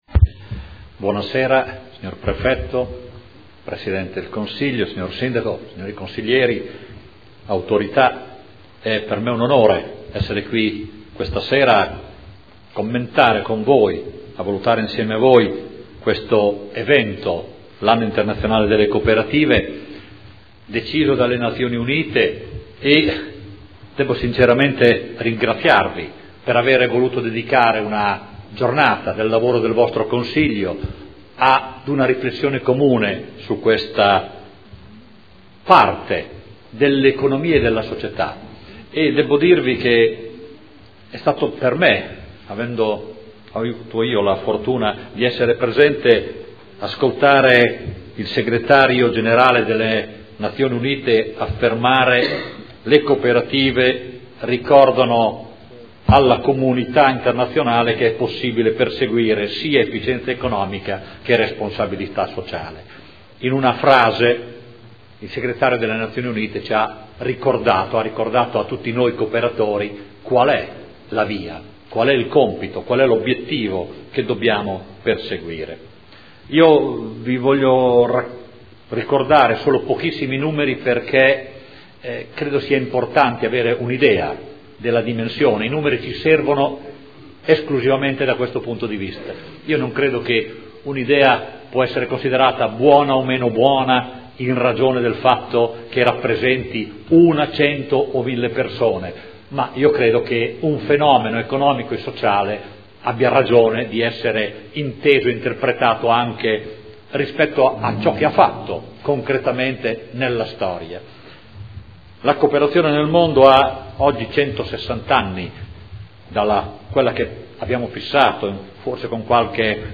Giuliano Poletti — Sito Audio Consiglio Comunale
Seduta del 22/11/2012. Intervento su celebrazione dell’Anno internazionale delle cooperative indetto dall’ONU per il 2012